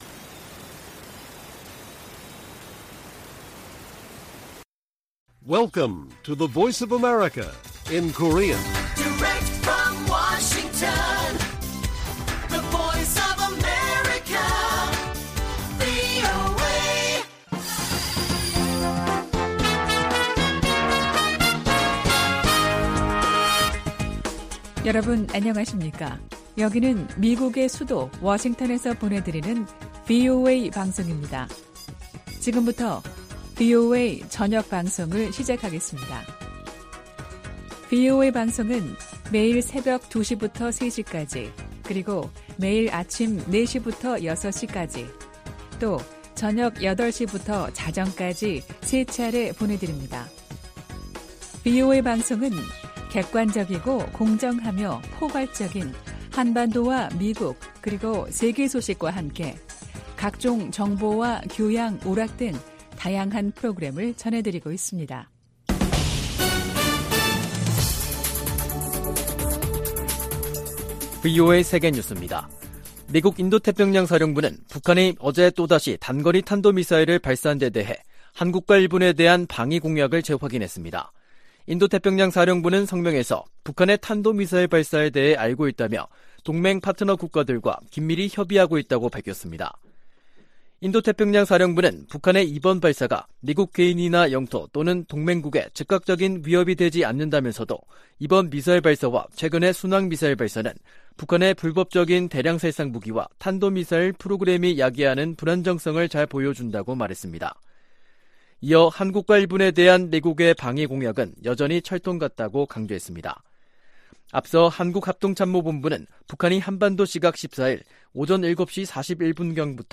VOA 한국어 간판 뉴스 프로그램 '뉴스 투데이', 2023년 3월 14일 1부 방송입니다. 미국과 한국이 ‘자유의 방패’ 연합훈련을 실시하고 있는 가운데 북한은 미사일 도발을 이어가고 있습니다. 백악관은 한반도 안정을 저해하는 북한의 어떤 행동도 용납하지 않을 것이라고 경고했습니다.